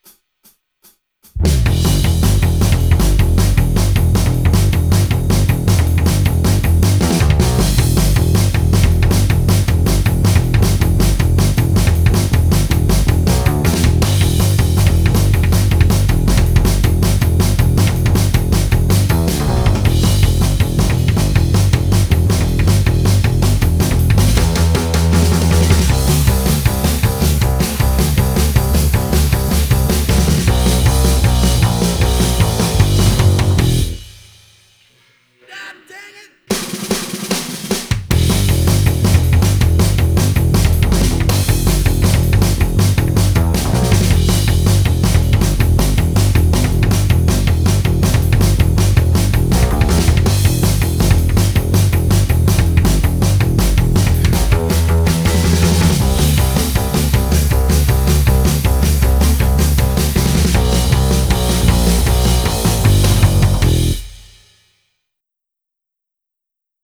consists of two instrumental (drum and bass only) rounds